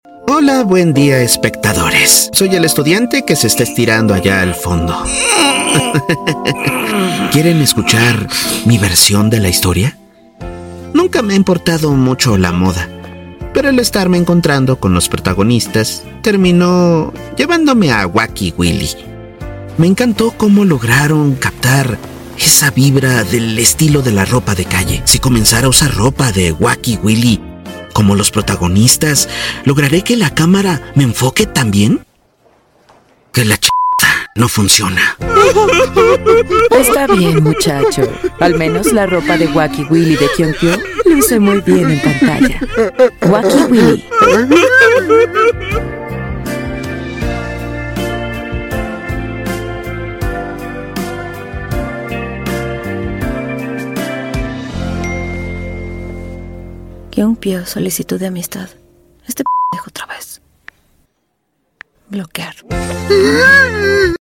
Un doblaje NO OFICIAL de los comerciales de la tienda de ropa coreana Wacky Willy. Éste es el Comercial No. 5, el último de esta serie de comerciales.